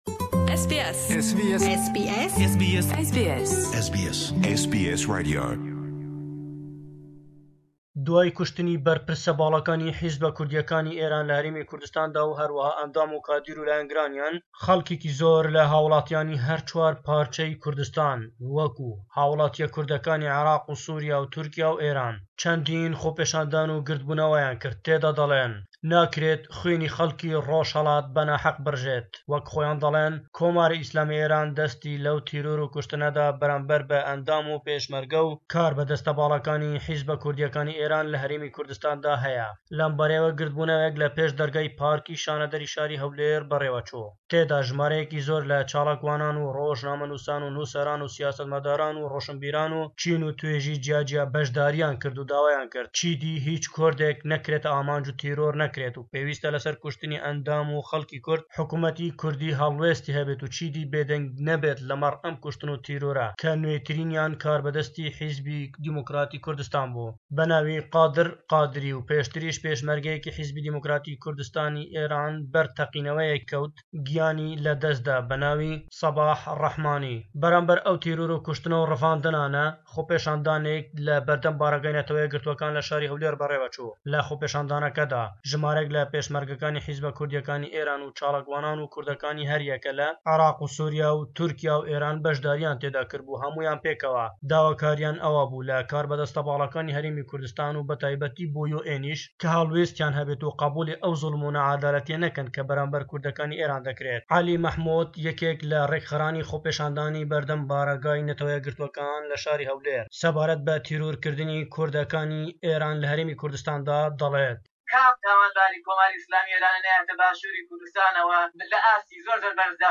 Raportî